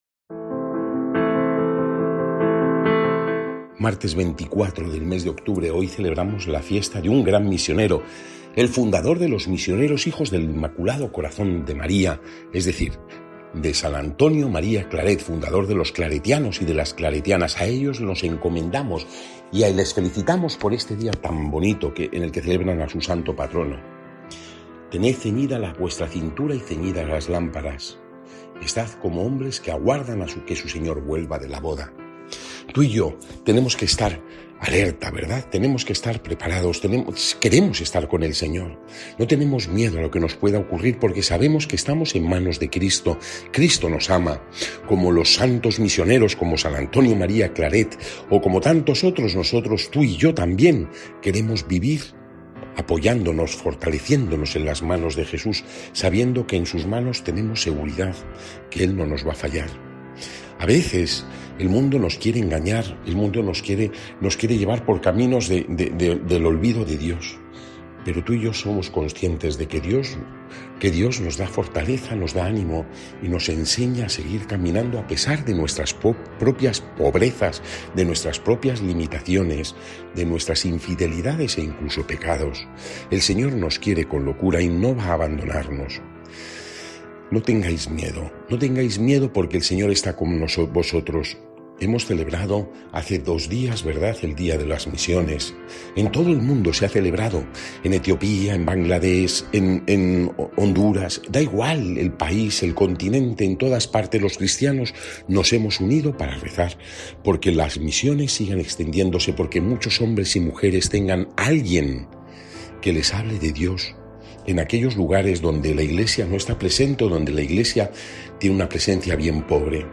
Evangelio según san Lucas (12, 35-38) y comentario